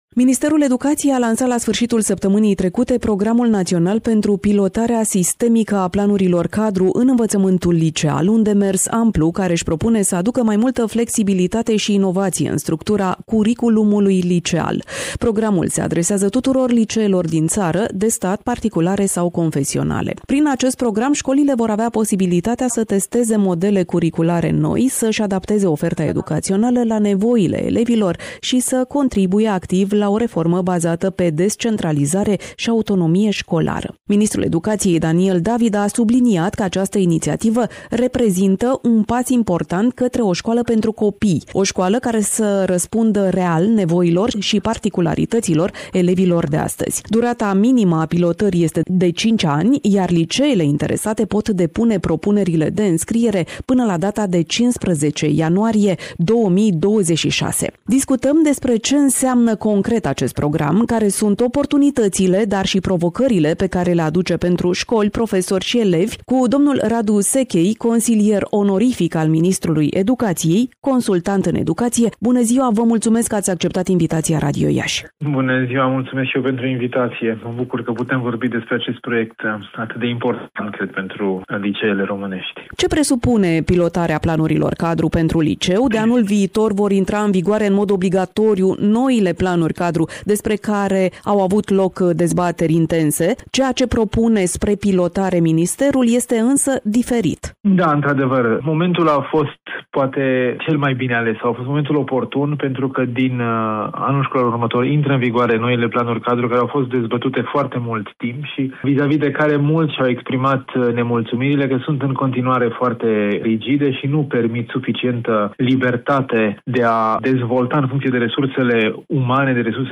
Varianta audio a inetrviului: Share pe Facebook Share pe Whatsapp Share pe X Etichete